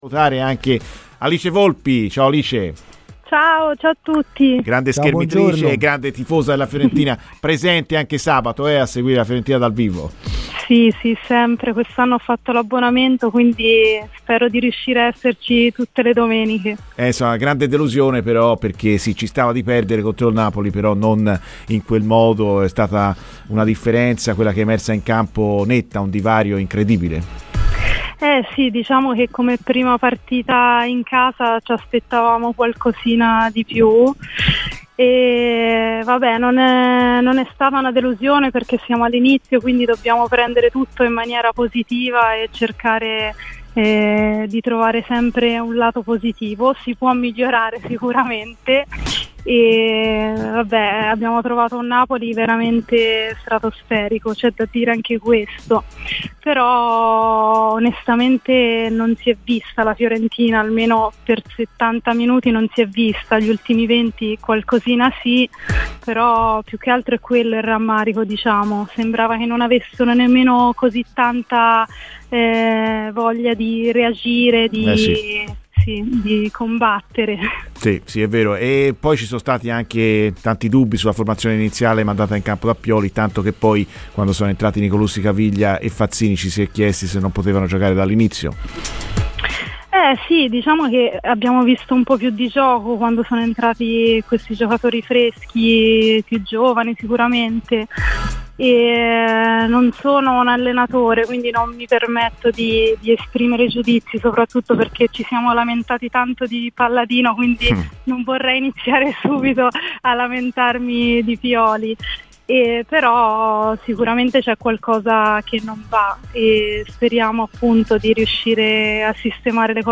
La schermitrice pluripremiata Alice Volpi, grande tifosa viola ha parlato così ai microfoni di Radio FirenzeViola, durante "Viola amore mio", di seguito le sue parole: "Come prima partita in casa ci potevamo sicuramente aspettare qualcosa in più, abbiamo trovato un grande Napoli ma per settanta minuti non abbiamo visto la vera Fiorentina".